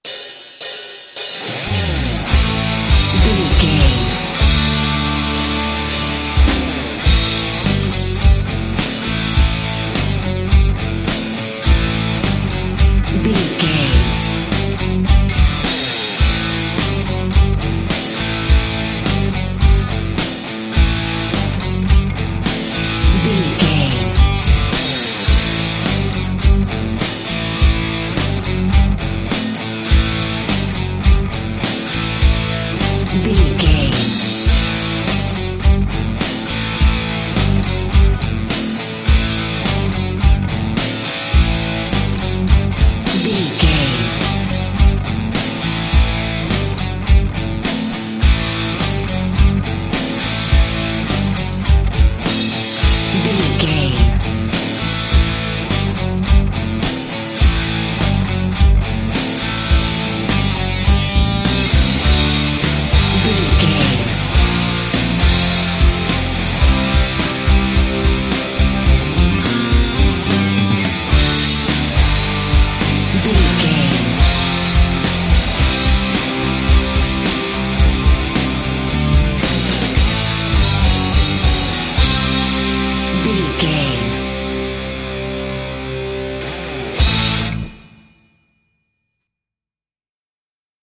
Ionian/Major
WHAT’S THE TEMPO OF THE CLIP?
drums
bass guitar
hard rock
lead guitar
aggressive
energetic
intense
nu metal
alternative metal